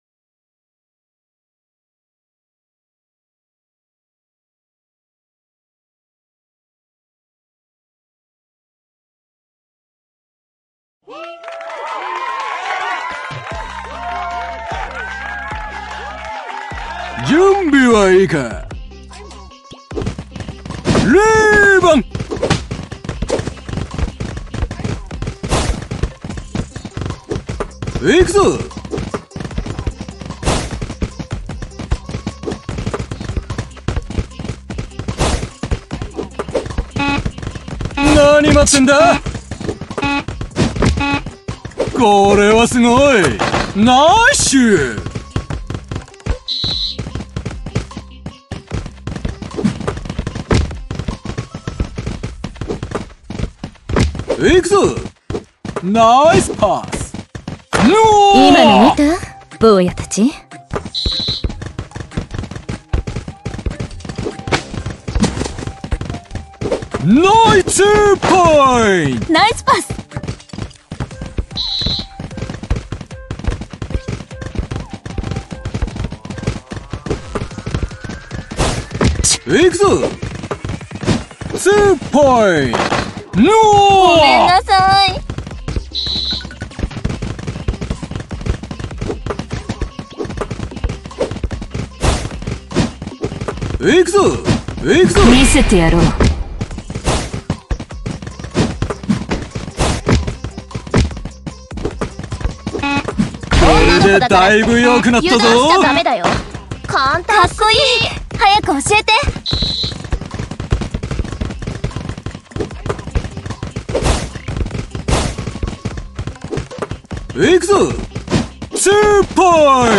3on3対戦ゲーム、アプリ《フィーバーダンク》のプレイ動画です。 実況は無しの試合している光景だけになりますのでご了承を。